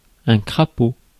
Prononciation
Synonymes paille Prononciation France: IPA: /kʁa.po/ Le mot recherché trouvé avec ces langues de source: français Traduction 1.